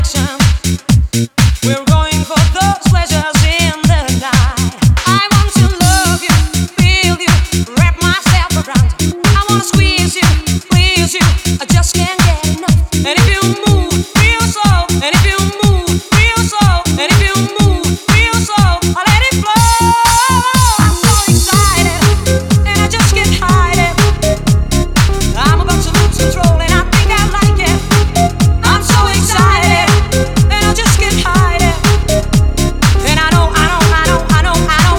Жанр: Хип-Хоп / Рэп / Танцевальные / Электроника